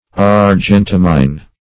Search Result for " argentamine" : The Collaborative International Dictionary of English v.0.48: Argentamine \Ar*gen"ta*mine\, n. Also -min \-min\ .